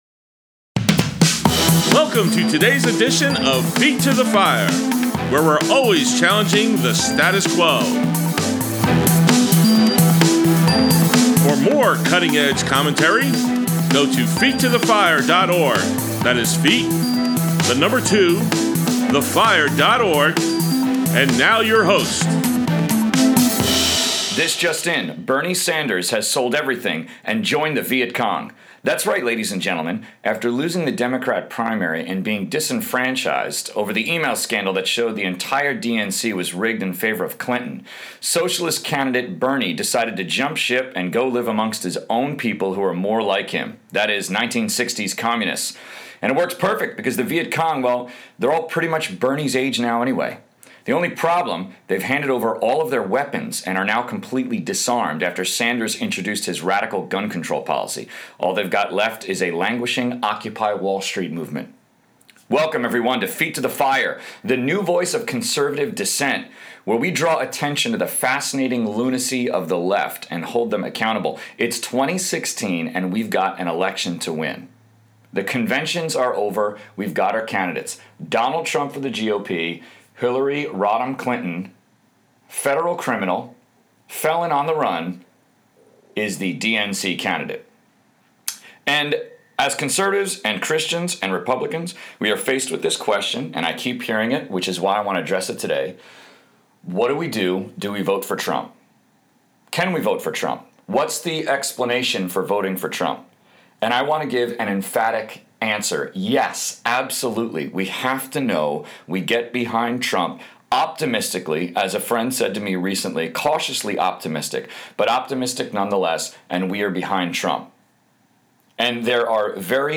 | Feet to the Fire Politics: Conservative Talk Show